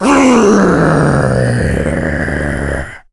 el_primo_start_vo_01.ogg